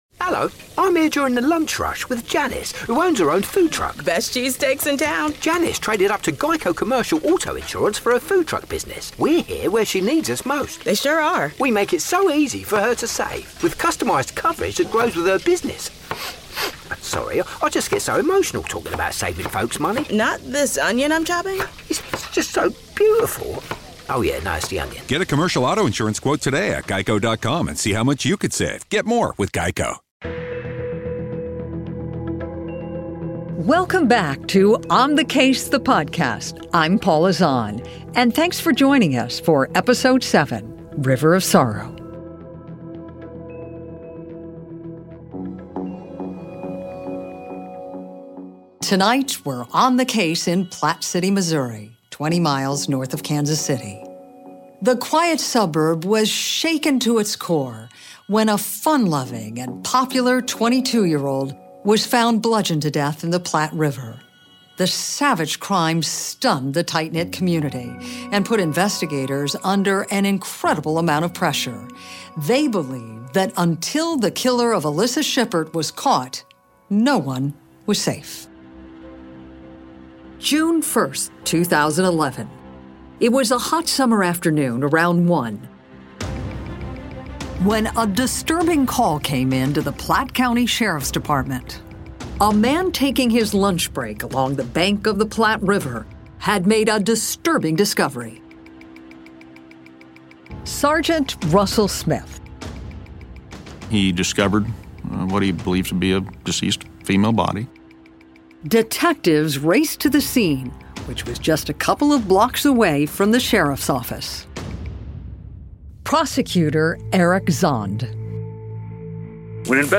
ON THE CASE with Paula Zahn is a true crime podcast that explores some of the most fascinating cases within our justice system. Each episode takes the listener on an in-depth exploration a single case, utilizing first person accounts, from family, witnesses and the key members of law enforcement.
The stories are woven together by Paula’s in-depth interviews, that take listeners through all the steps that led to solving a complex murder, including all its emotional twists and turns.